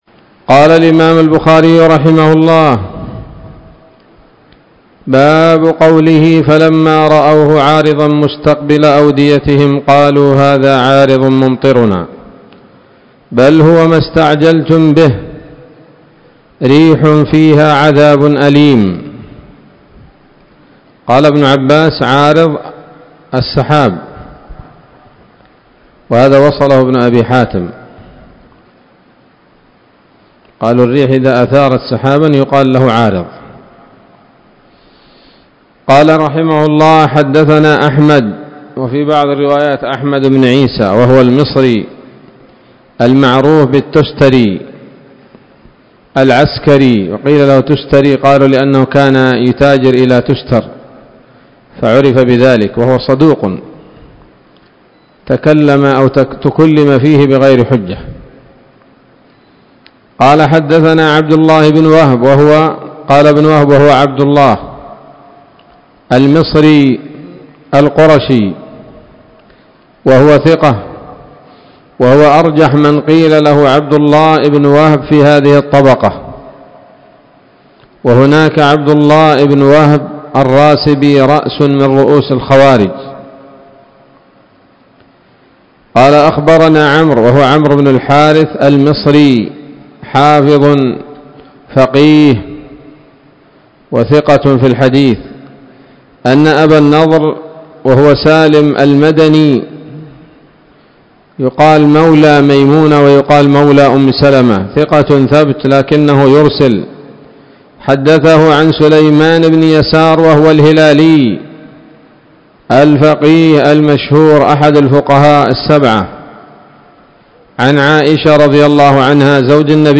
الدرس التاسع والعشرون بعد المائتين من كتاب التفسير من صحيح الإمام البخاري